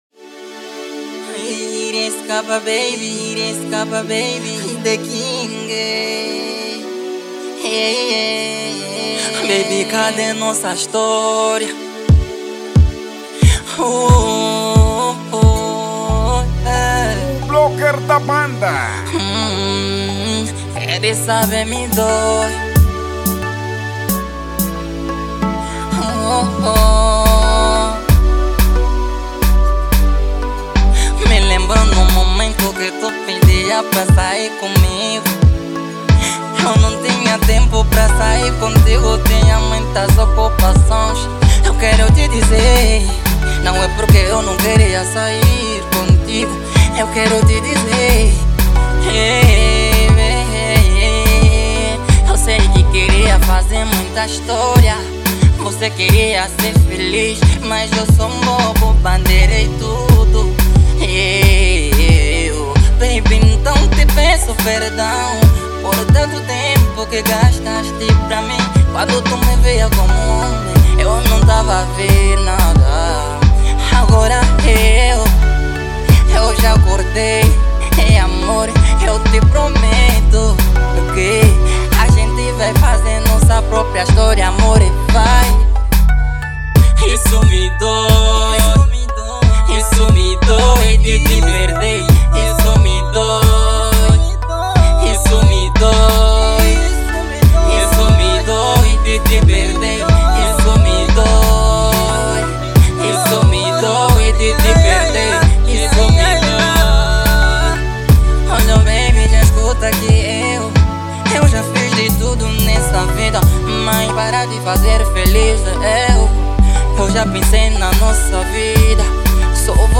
Gênero : Kizomba